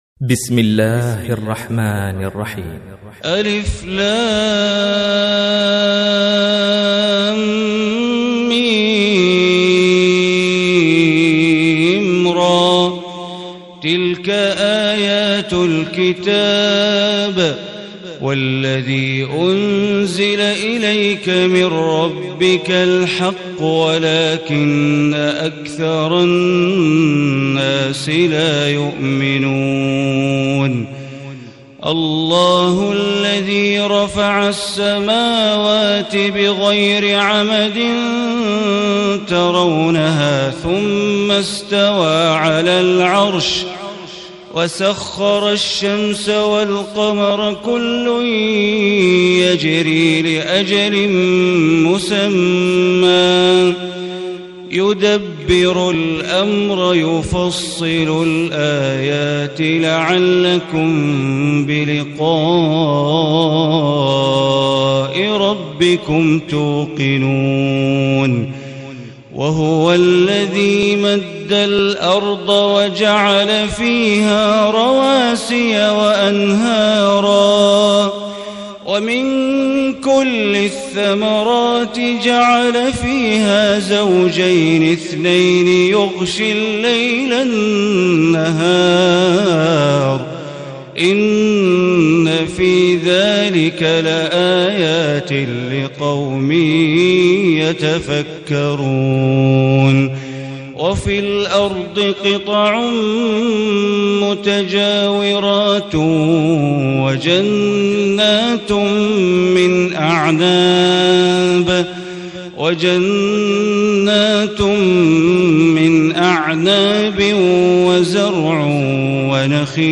Surah Ar Raad Recitation by Sheikh Bandar Baleela
Surah Ar Raad, listen online or download audio recitation in the beautiful voice of Sheikh Bandar Baleela.